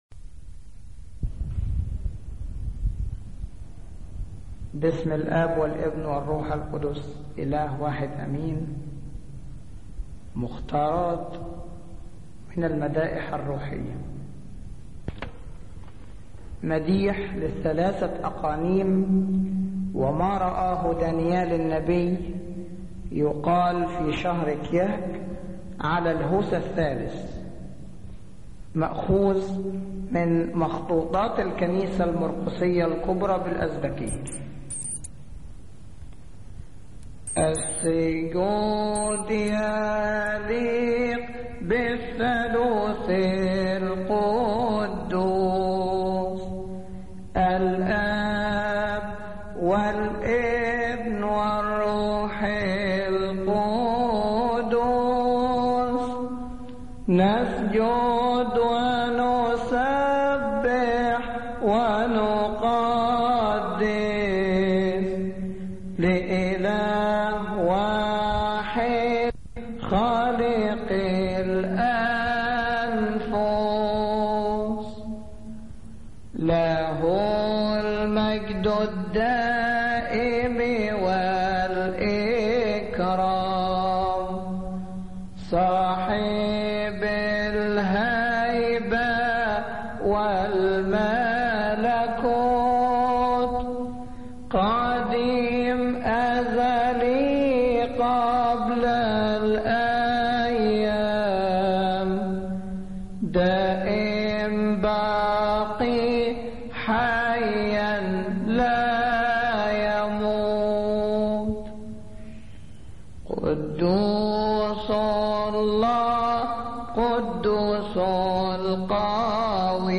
مديح كيهكي